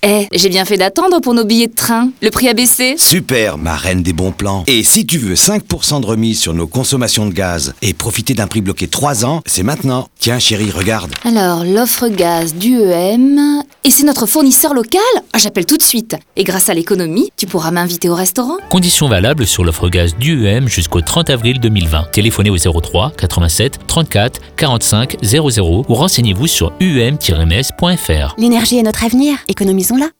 Campagne radio UEM gaz